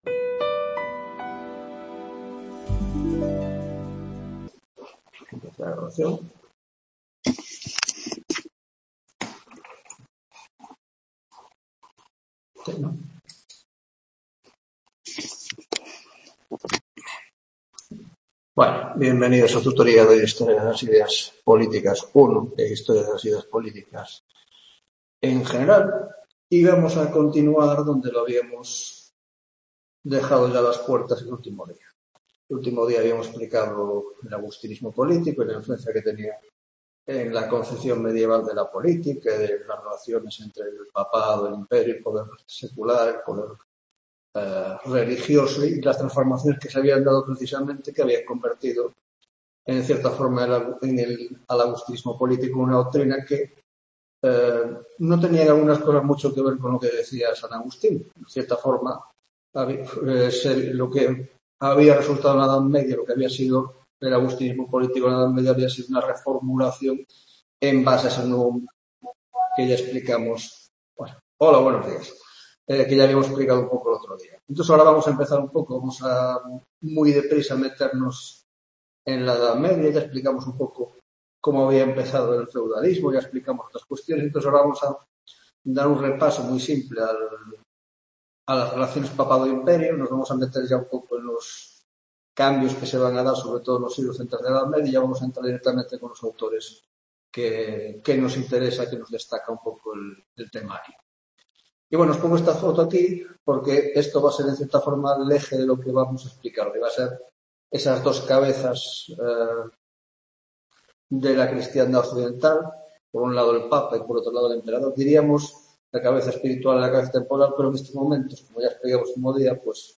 10ª Tutoría de Historia de las Ideas Políticas (Grado de Ciencias Políticas y Grado de Sociología)